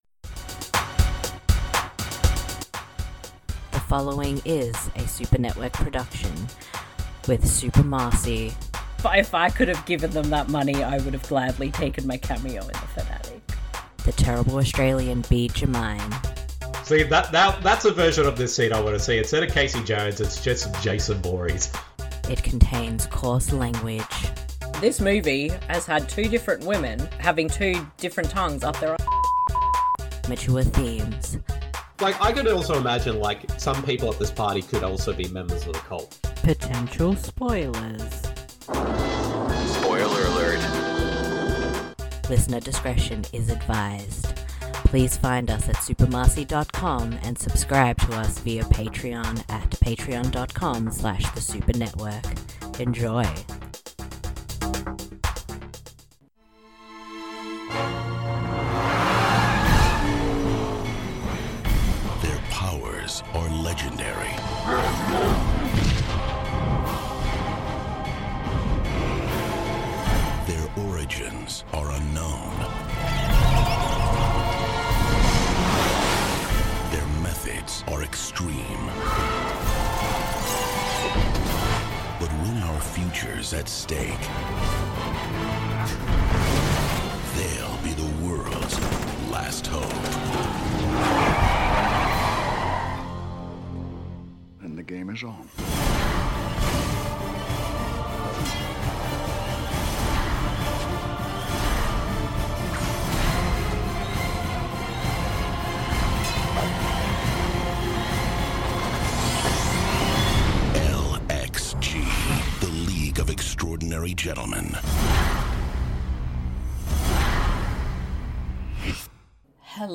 You can listen to it while you watch the film or listen to it on its own. Highlights include: * Warning, this podcast contains impressions of Sean Connery!
* Yeah … Nic Cage impressions are here too.